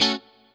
CHORD 2   AC.wav